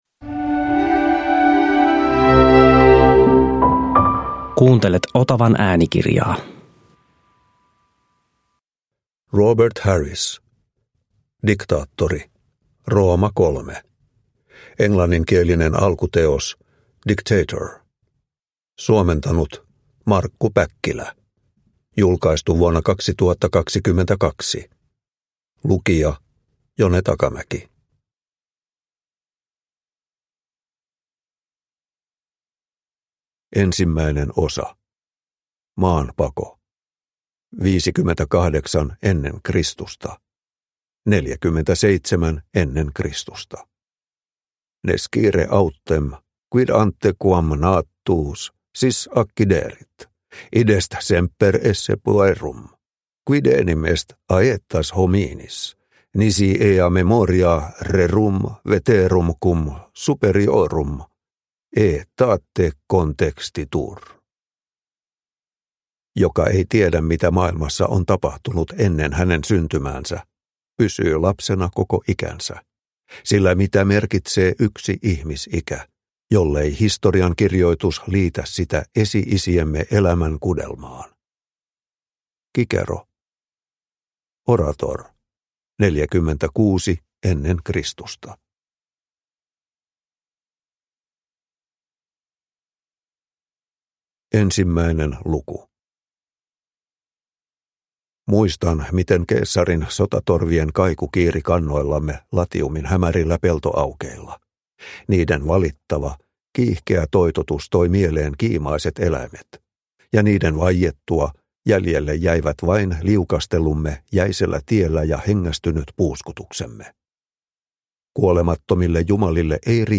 Diktaattori – Ljudbok – Laddas ner